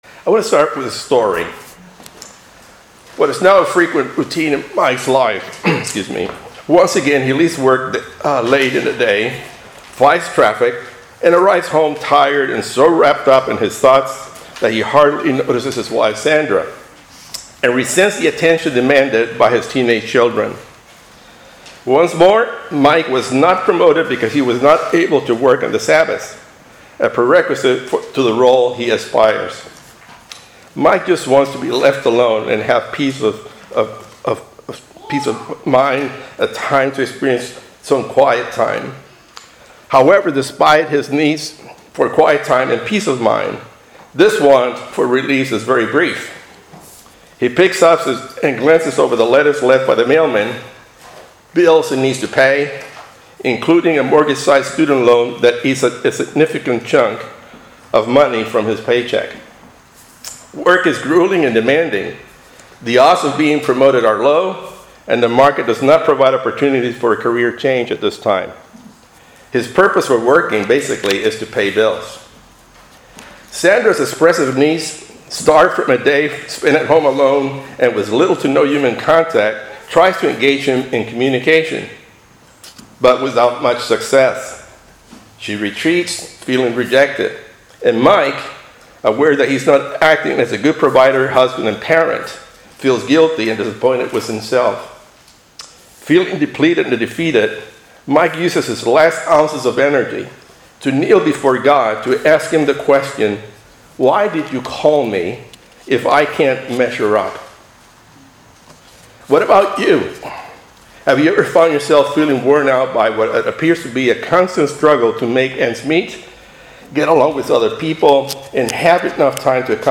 Split Sermon.